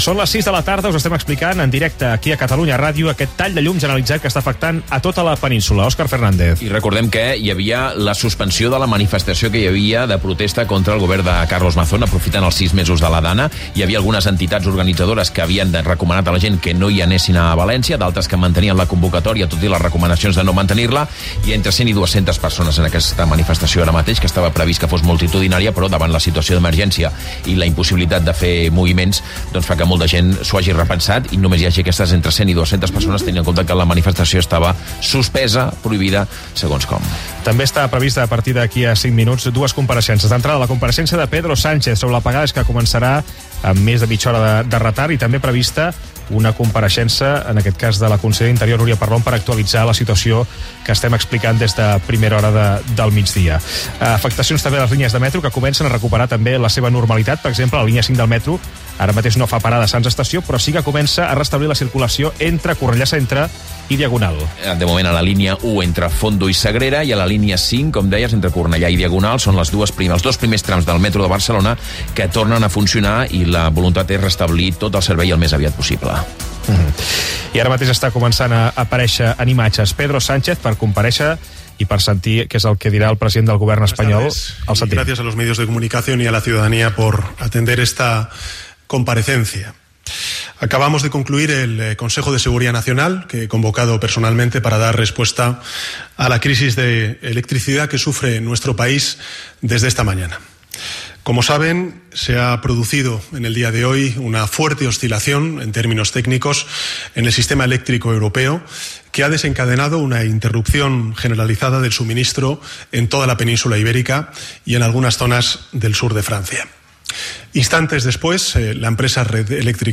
compareixença del president del govern espanyol Pedro Sánchez
Gènere radiofònic Informatiu
Banda FM